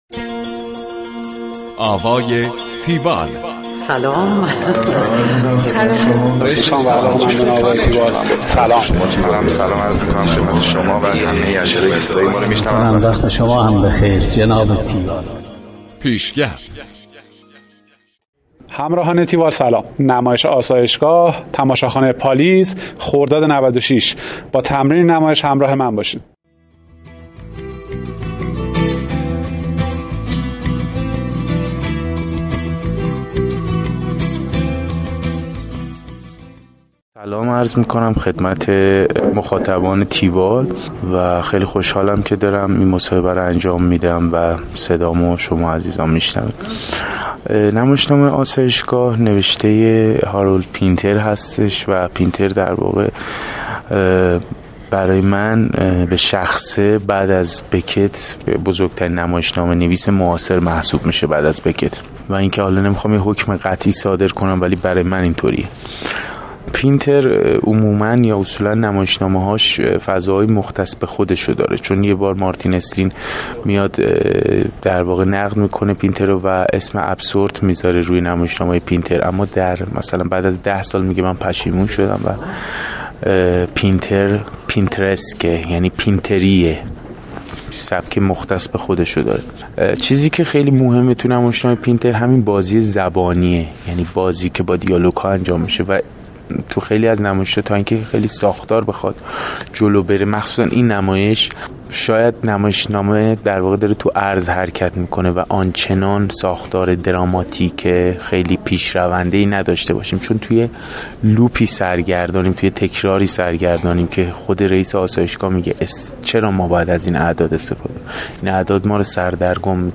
گزارش آوای تیوال از نمایش آسایشگاه